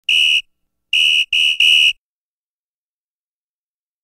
دانلود آهنگ سوت داور فوتبال 3 از افکت صوتی انسان و موجودات زنده
جلوه های صوتی
دانلود صدای سوت داور فوتبال 3 از ساعد نیوز با لینک مستقیم و کیفیت بالا